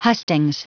Prononciation du mot hustings en anglais (fichier audio)
Prononciation du mot : hustings
hustings.wav